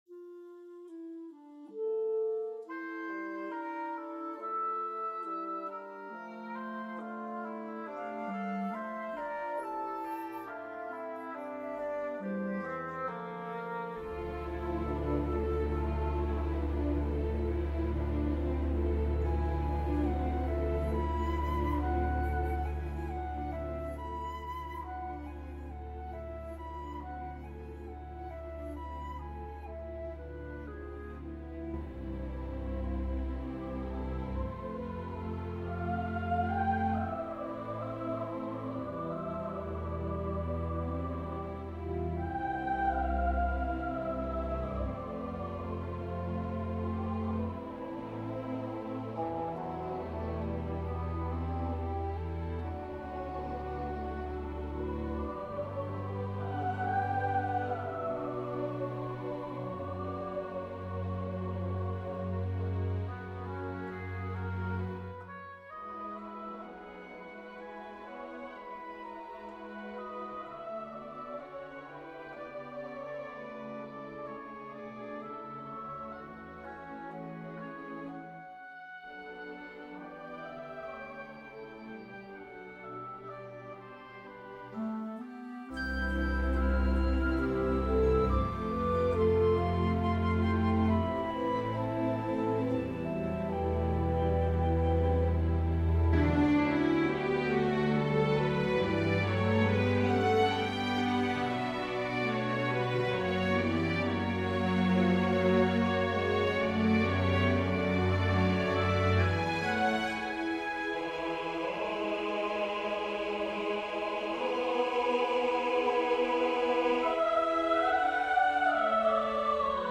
SATB chorus, piano